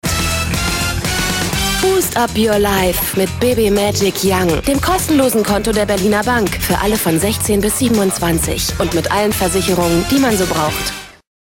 deutsche Sprcherin, schön, jung, klar, flexibel
Sprechprobe: Industrie (Muttersprache):
german female voice over artist